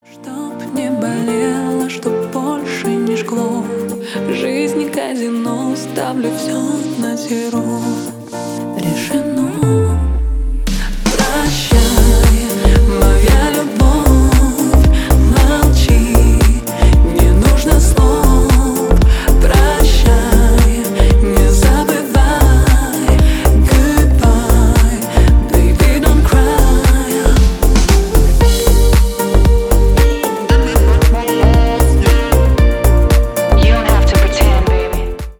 Поп Музыка
клубные # спокойные